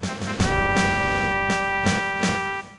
trumpet.ogg